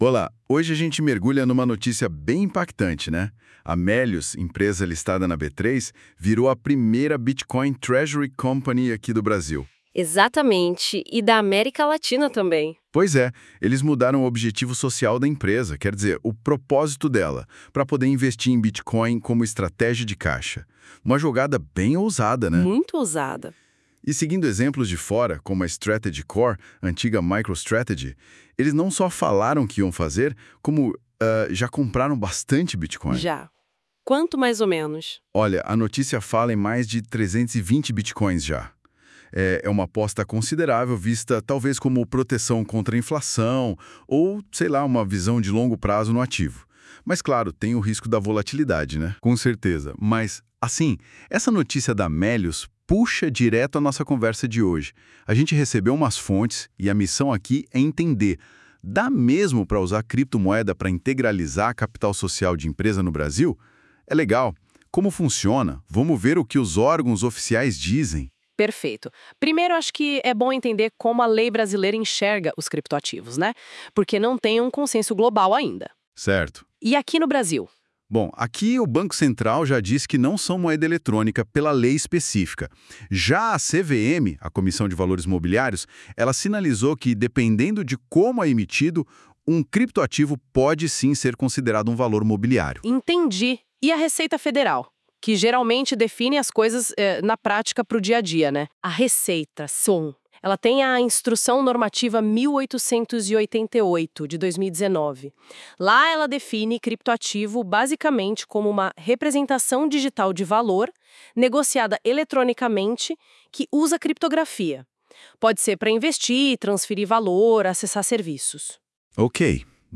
Escute também o nosso podcast, gerado por IA, comentando o assunto: